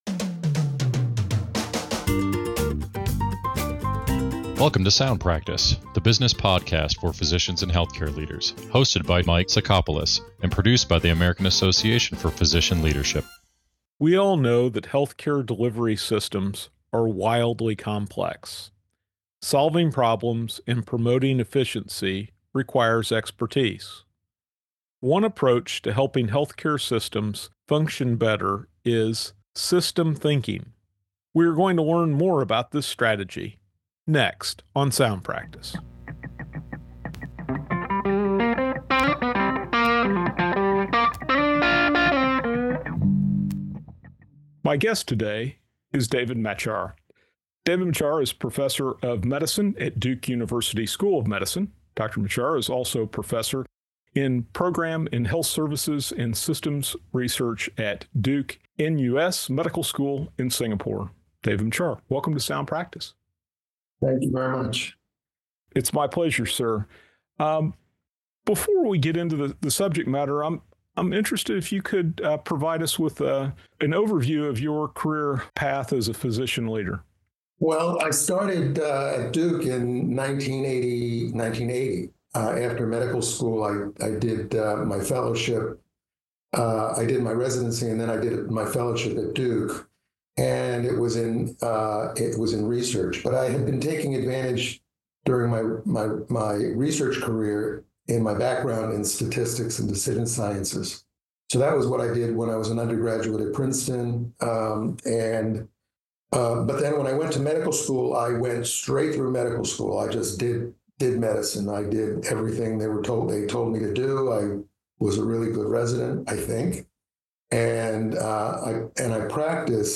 Systems Thinking in Healthcare: A Conversation